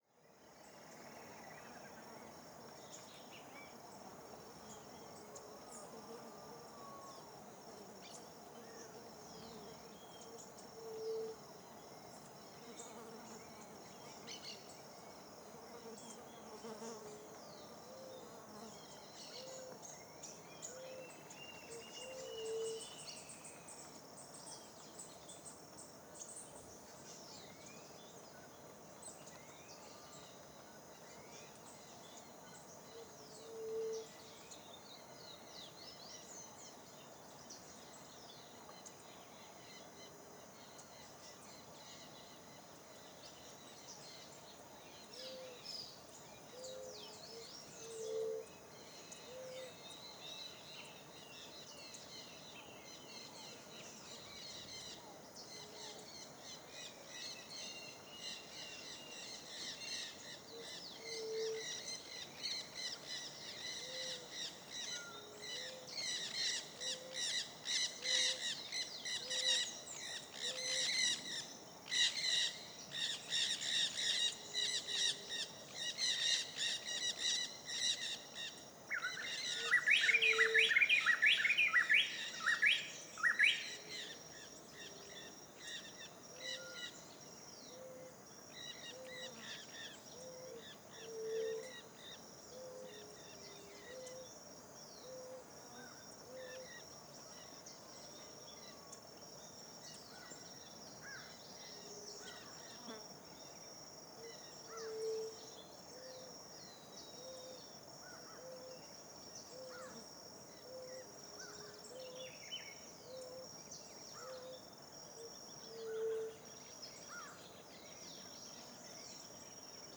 CSC-05-111-OL- Ambiente fim de tarde perto do rio com passaros e insetos.wav